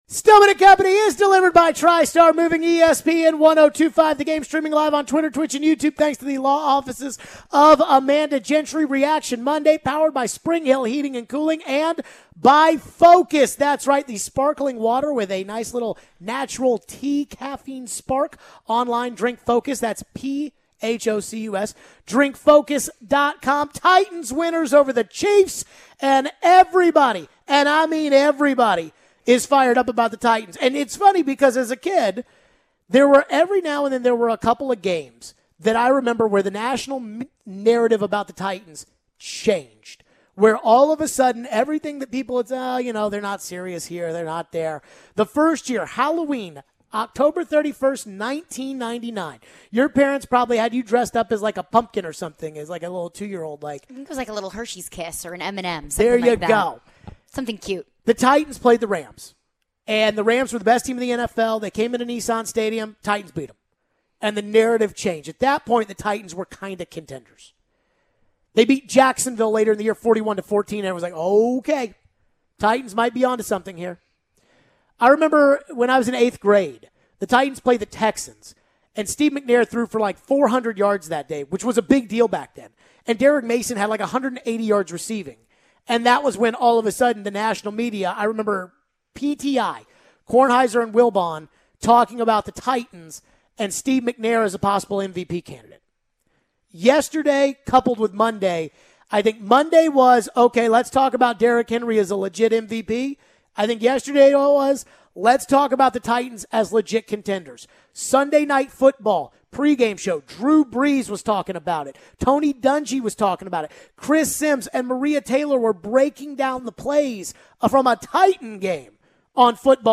Can Henry carry the team? We take your phones.